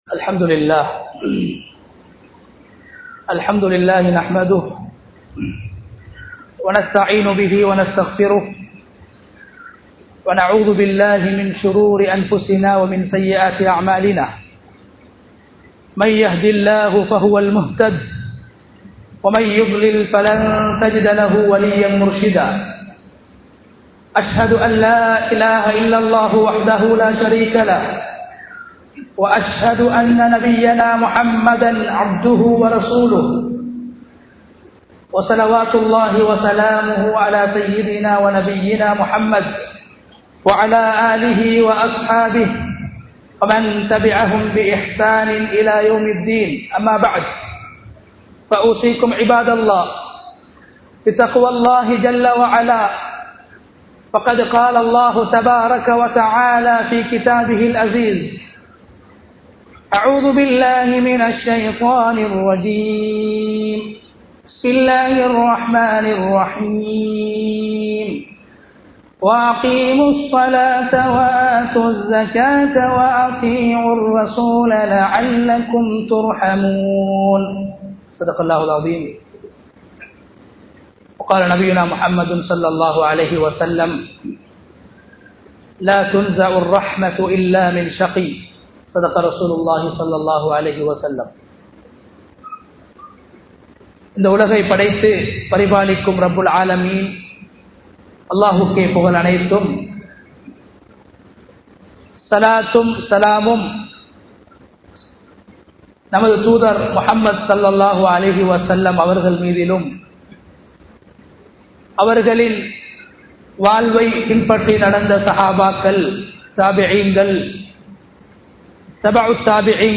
அல்லாஹ்வின் அருளும் சுவனமும் | Audio Bayans | All Ceylon Muslim Youth Community | Addalaichenai
Munawwara Jumua Masjidh